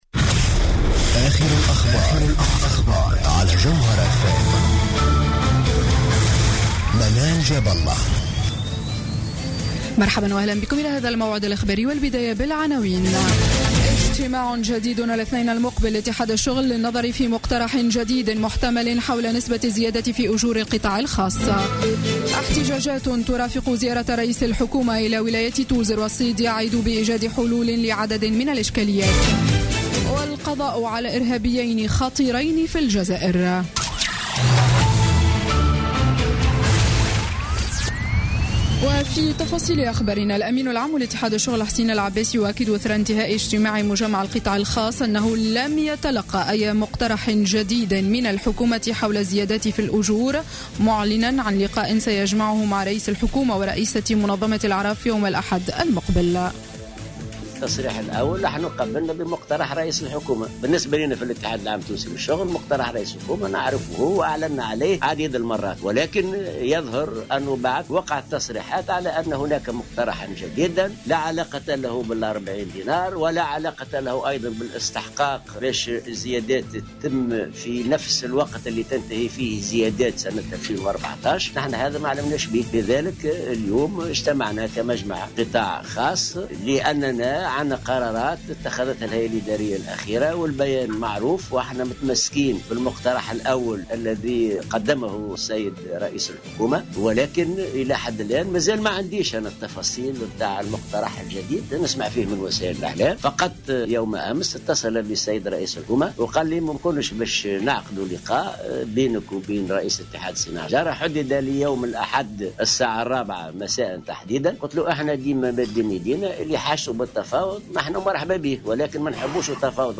نشرة أخبار السابعة مساء ليوم الجمعة 25 ديسمبر 2015